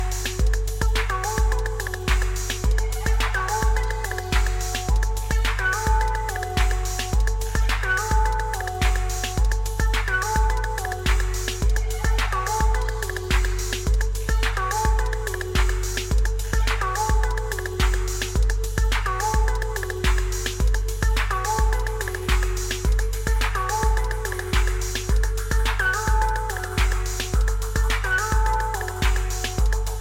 a música aqui é alienígena e minimalista